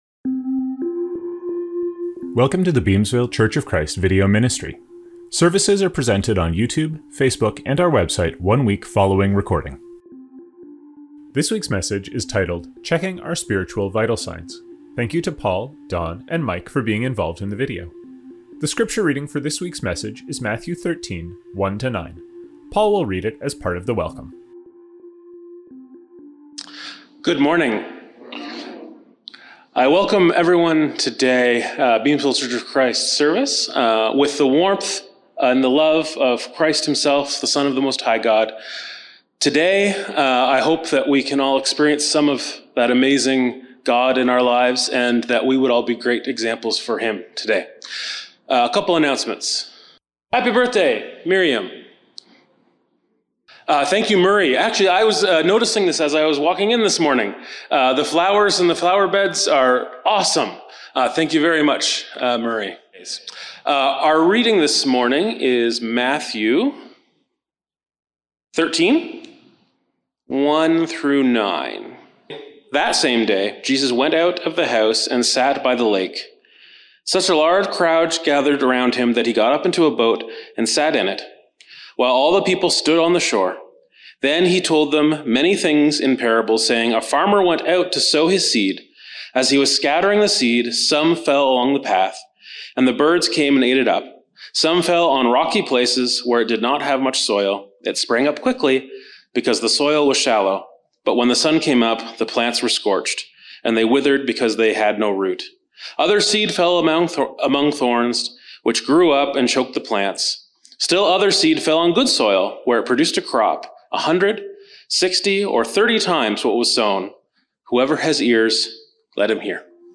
Sermon: Matthew 13:10; 12:46-50; 13:1-13; Galatians 5:22-23; Mark 3:20-21; John 7:1-5; 7:25; 7:26; 1:11; Mark 4:30-34.